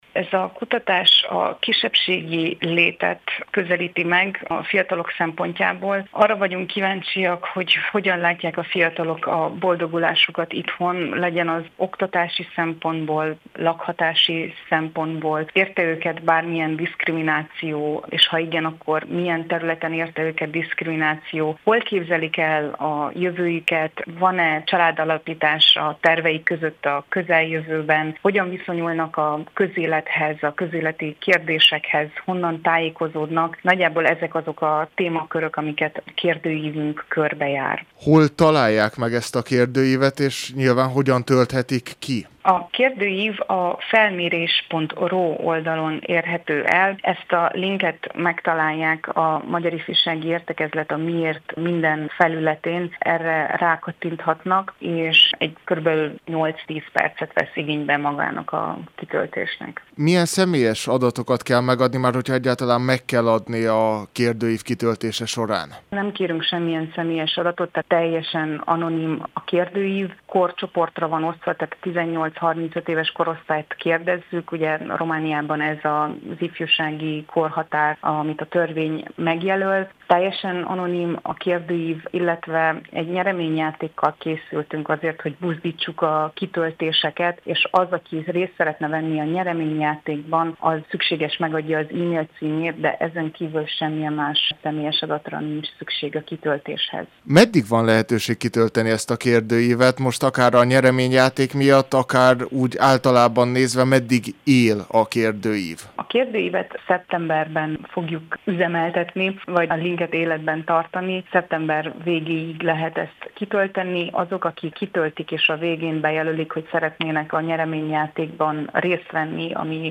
beszélget.